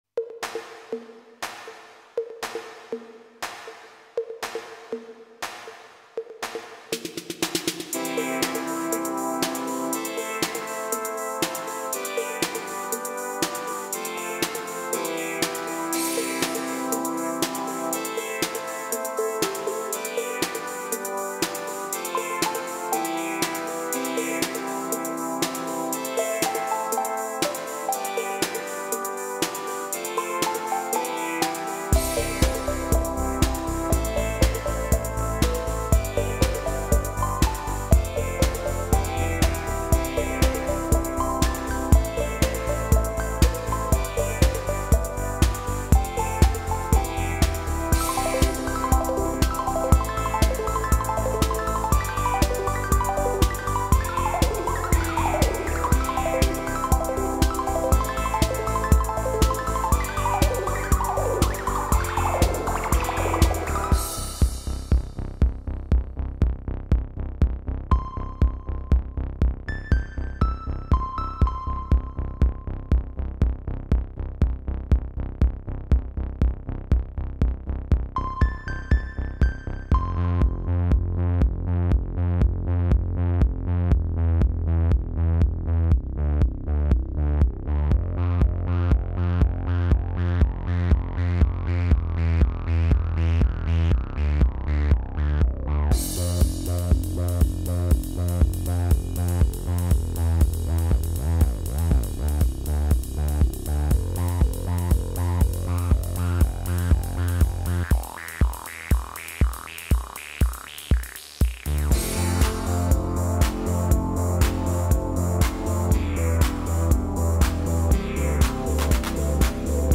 Cheery little live tune with some pretty furry bass
• Bass - Behringer Wasp
• Chords - Behringer Deepmind 6
• Beats - Behringer RD-8, Alesis SR-16